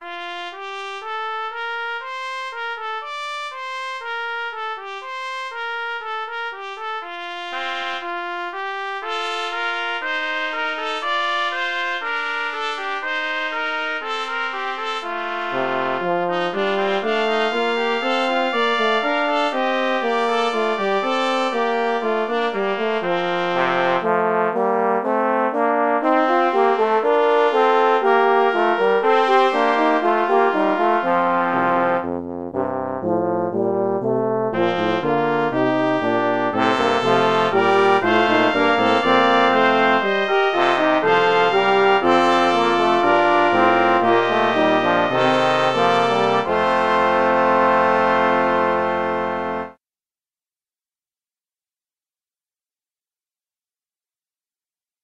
Brass Quintet